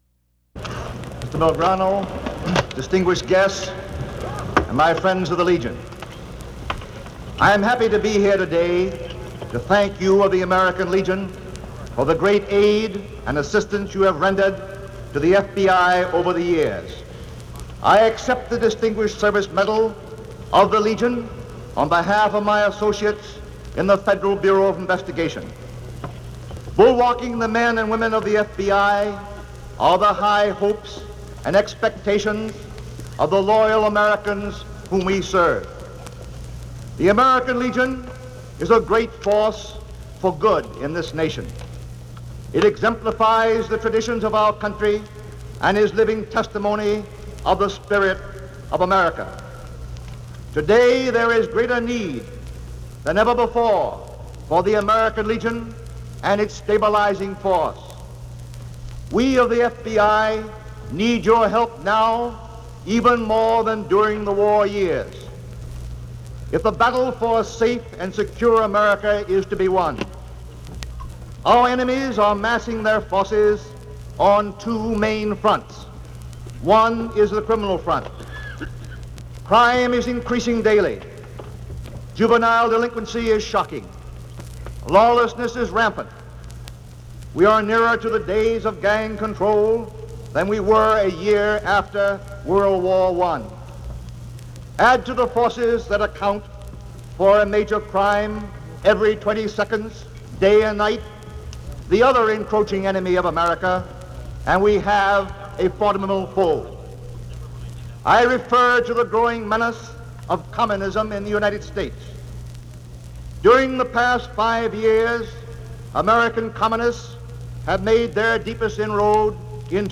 J. Edgar Hoover gives a speech at the acceptance of honorary award from the American Legion, in San Francisco